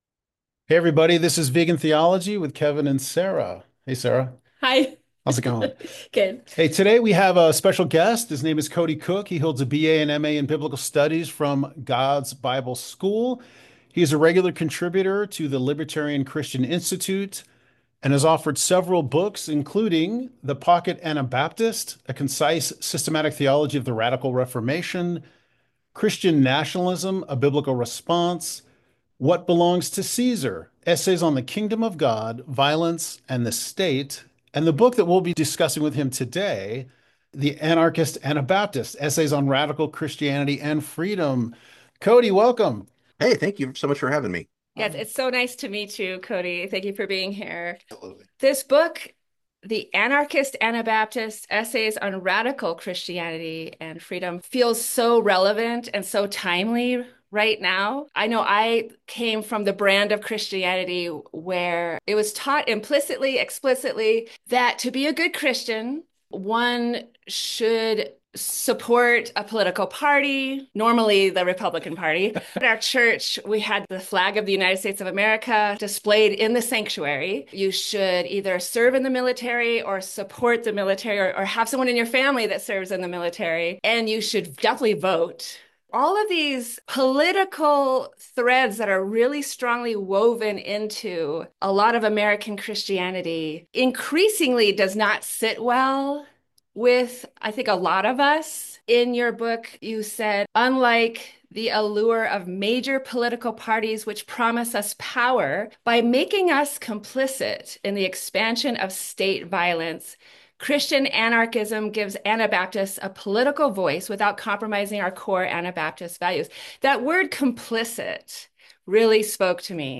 It was a fun and enlightening conversation.